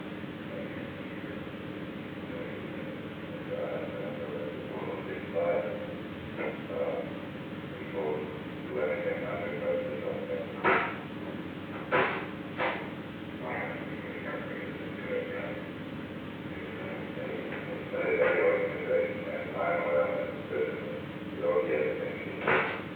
Secret White House Tapes
Location: Executive Office Building
The President listened to a portion of a recording of a meeting with Henry A. Kissinger.
The President stopped the recording at an unknown time before 12:51 pm.